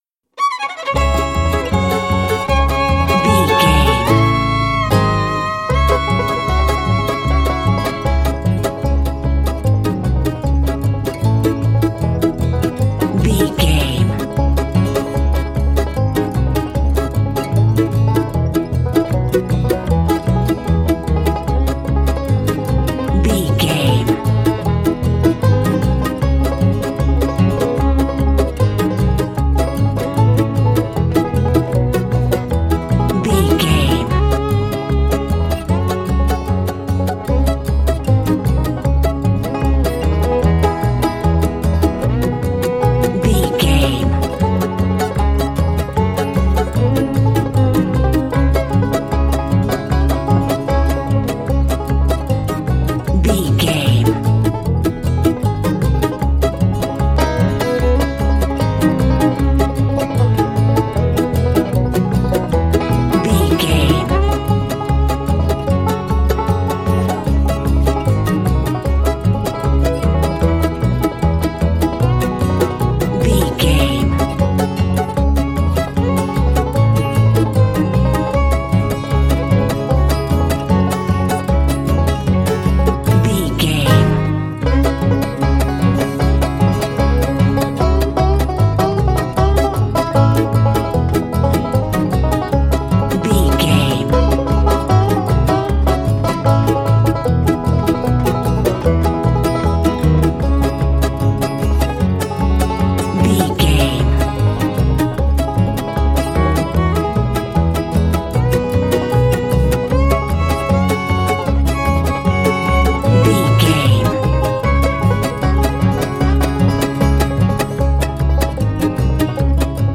Aeolian/Minor
C#
Fast
banjo
violin
double bass
Pop Country
country rock
bluegrass
uplifting
driving
high energy